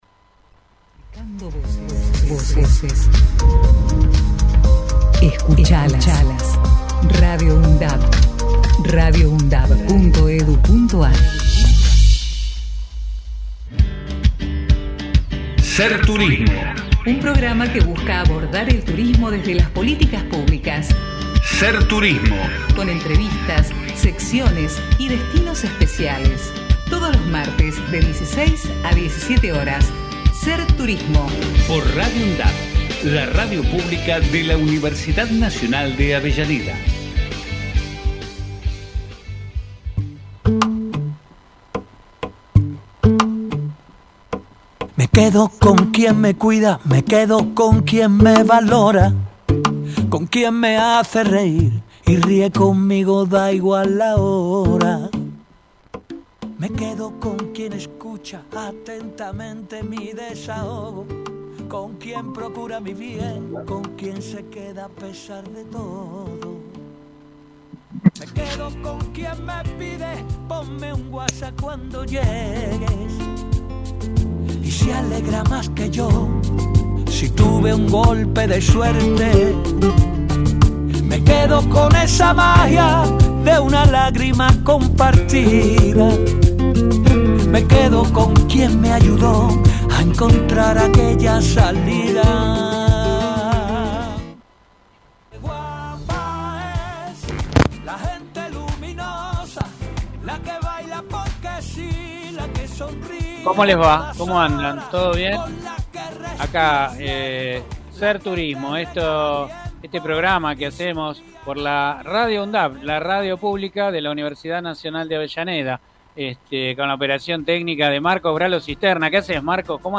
Ser Turismo Texto de la nota: SER TURISMO Un programa que busca abordar el Turismo desde las Políticas Públicas, realizado por estudiantes de las Carreras de Turismo de la Universidad Nacional de Avellaneda, nos moviliza conocer y aprender sobre cada detalle de nuestra actividad y observamos las dificultades o temas pendientes de resolución en un espacio de reflexión y debate. Con entrevistas, secciones y destinos especiales, todos los martes de 16 a 17 horas Integrantes